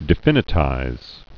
(dĭ-fĭnĭ-tīz, dĕfĭ-nĭ-)